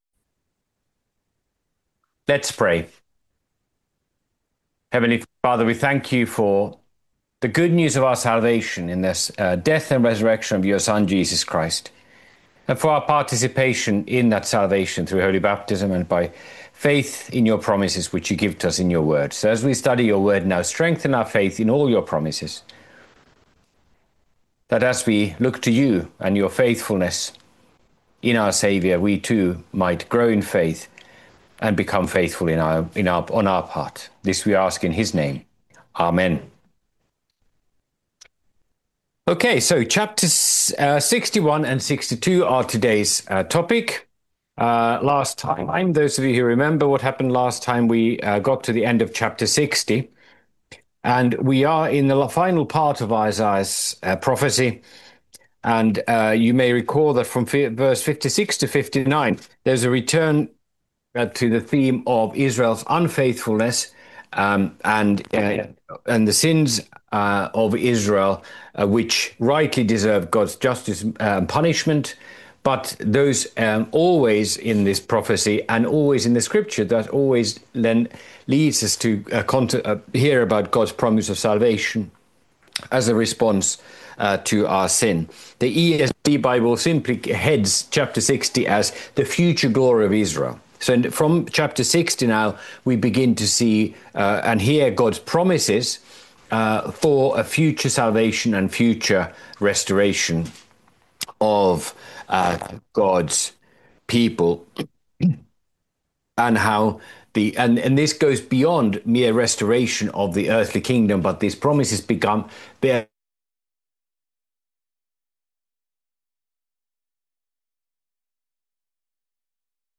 by admin | Apr 16, 2026 | Bible Studies, Isaiah